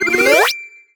collect_item_21.wav